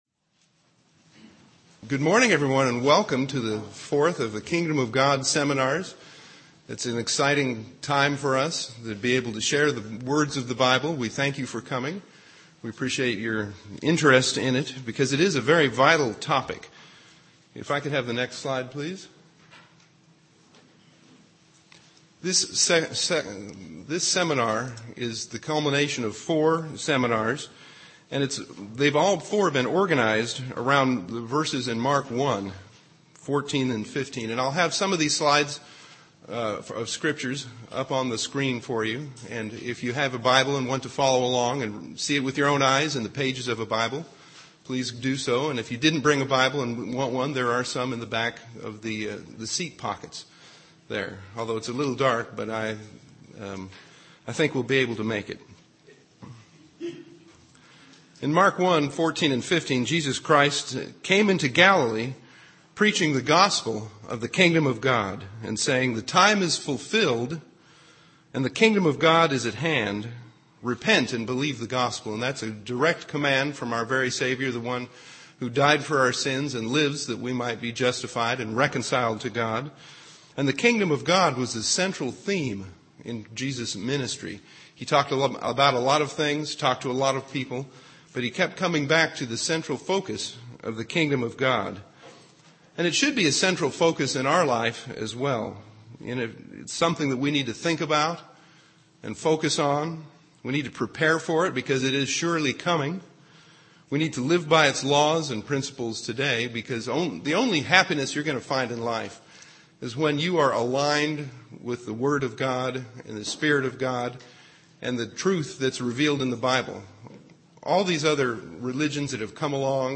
This message was given for a Kingdom of God seminar.
Given in Ft. Wayne, IN
UCG Sermon Studying the bible?